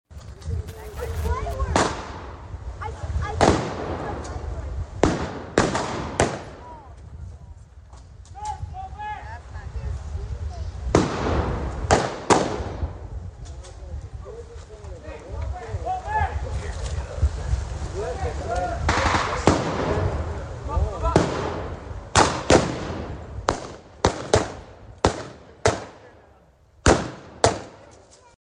After the traditional activities, re-enactors depicted a World War II battle at the old Kansas Highway 99 bridge.